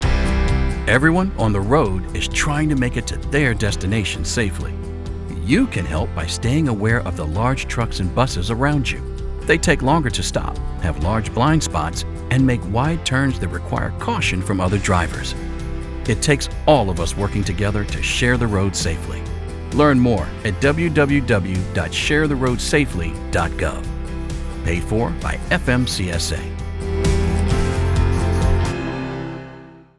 Audio Public Service Announcements (PSAs)